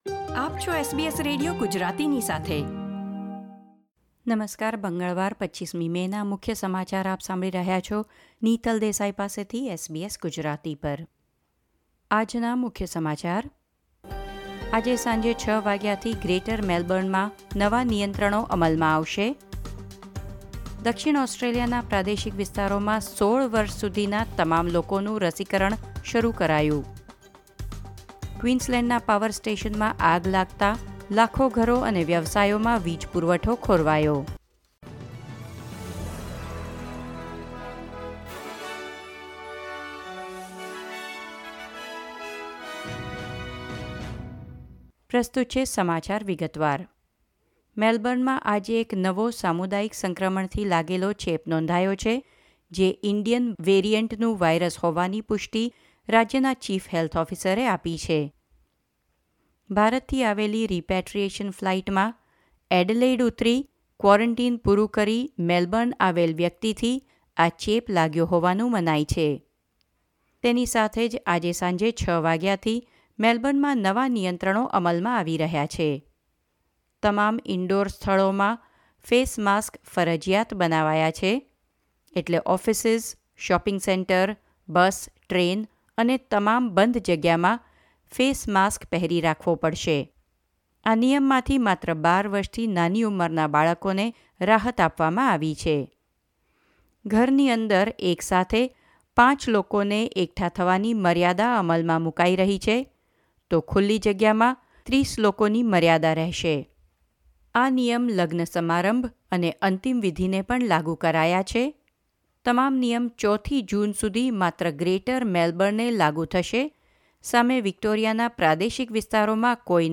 SBS Gujarati News Bulletin 25 May 2021
gujarati_2505_newsbulletin.mp3